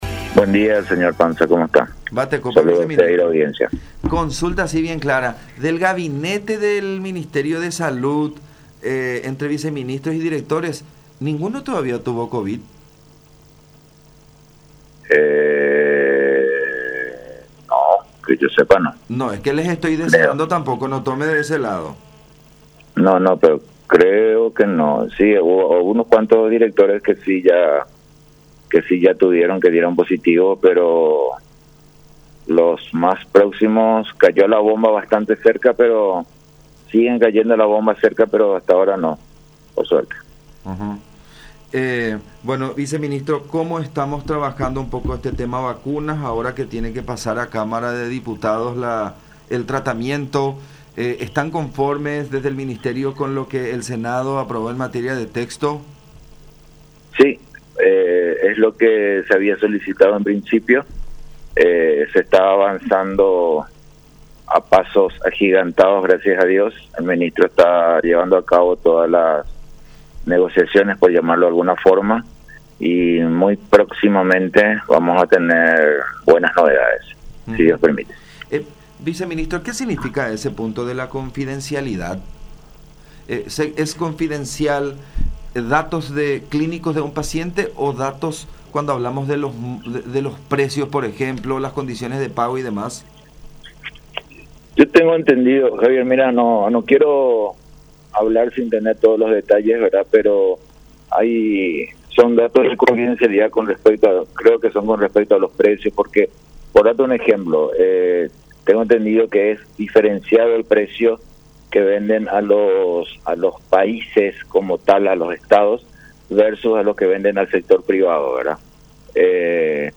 “Se está avanzando a pasos agigantados gracias a Dios. El ministro está llevando adelante las negociaciones y en breve tiempo vamos a tener novedades (sobre las vacunas). Son cinco las empresas con las cuales se está negociando”, dijo Borba en conversación con La Unión.